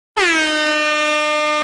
Dancehall Horn